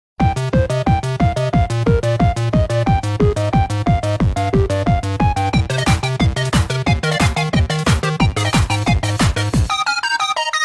• Качество: 128, Stereo
без слов
Весёлый рингтон смс